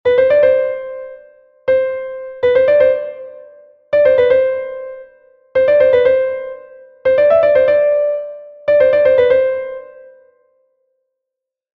Doppelschlag, Gruppetto [italienisch], Assemblage oder Doublé [französisch]. Eine Spielmanier und Gesangsverzierung.